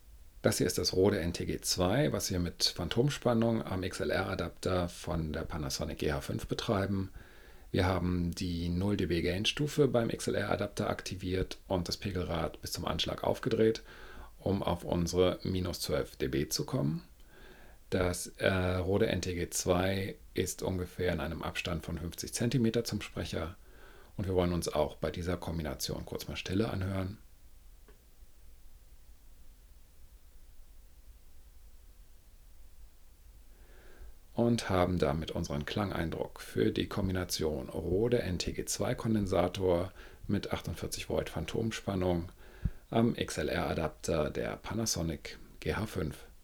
Bei der 0dB Gain Stufe mussten wir das Pegelrad des XLR-Verstärkers bis zum Maximum aufdrehen um auf unsere -12dB Vergleichspegel zu kommen. Das Mikro befindet sich ca. 50 cm vom Sprecher entfernt:
Rode NTG2 +48V bei 0dB Boost am XLR-Adapter
Hier hören wir einen minimalen Vorteil bei der 0dB Boost Aufnahme.
RodeNTG2_Phantom_XLR_GH5_0dBGain_norm.wav